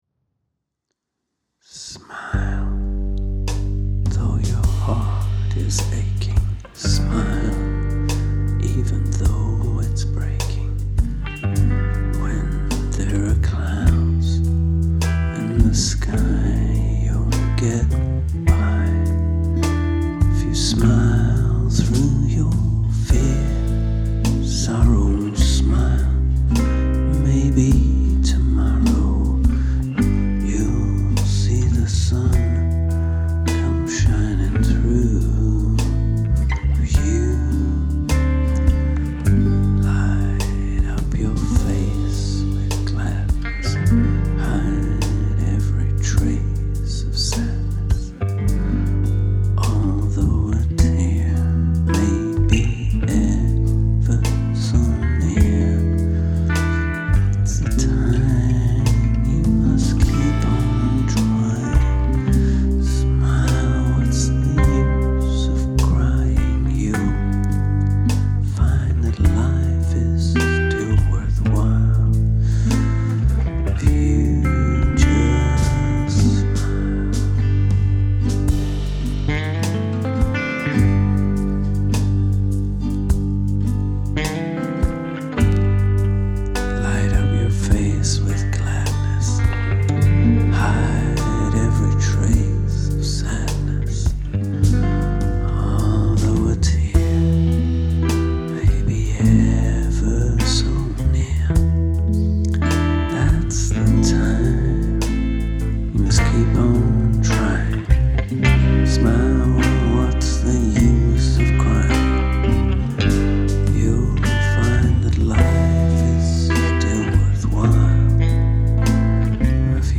fairly roughly…